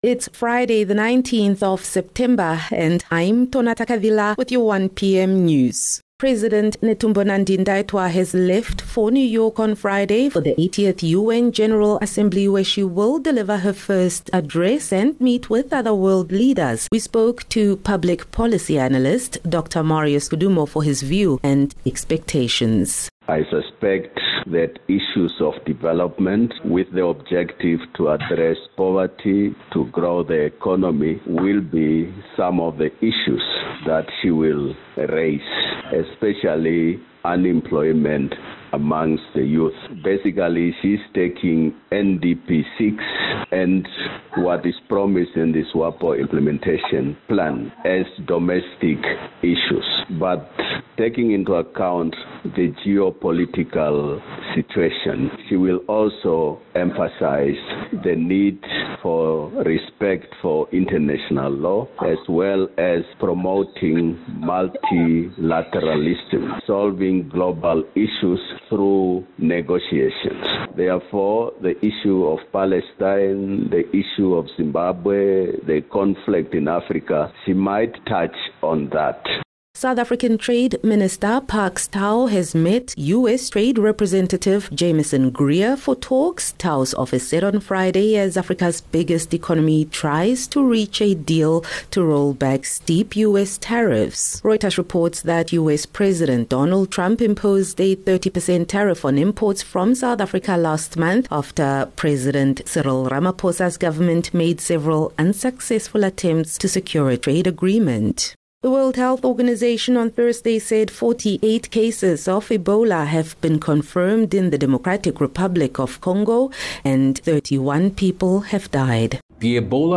19 Sep 19 September - 1 pm news